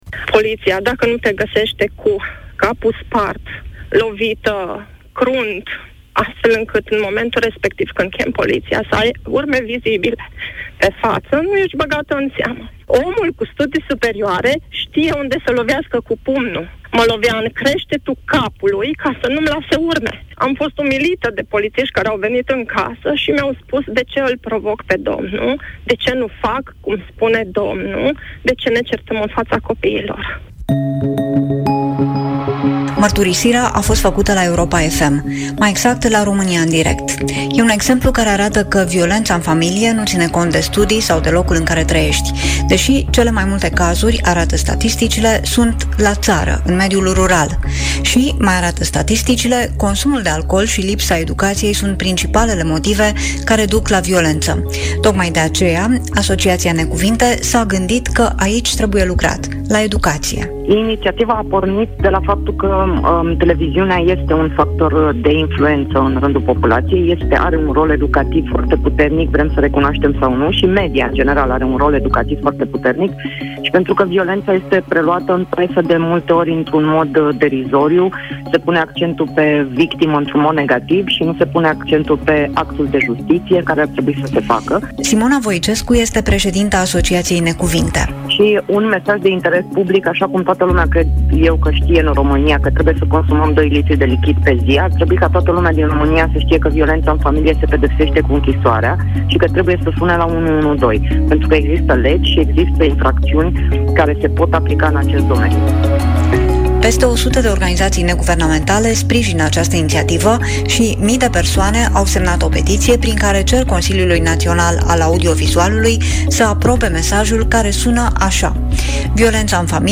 Reportaj: „Violența în familie se pedepsește cu închisoarea. Sună la 112”
Reportaj-Violenta-femei.mp3